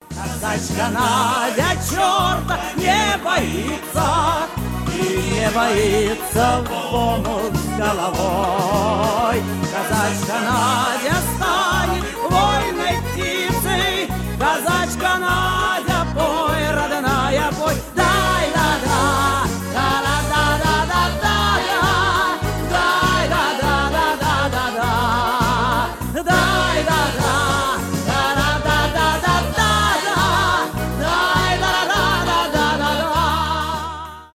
эстрадные , казачьи